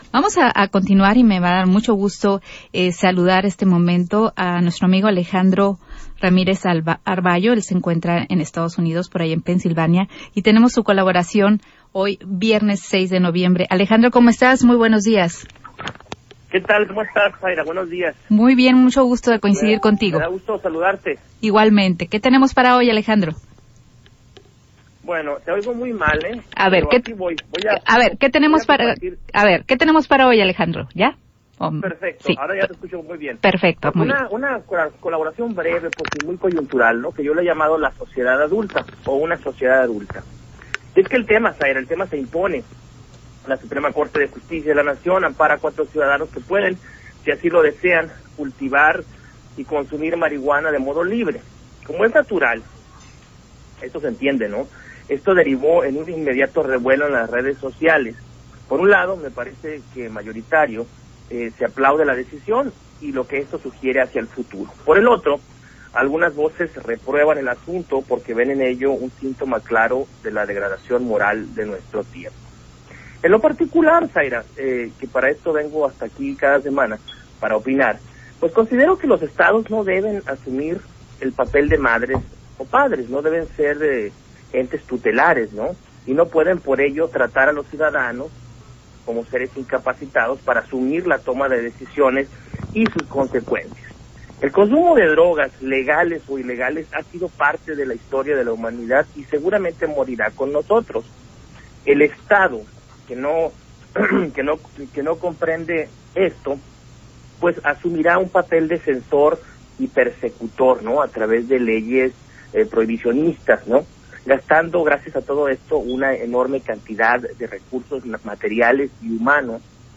Transmisión en radio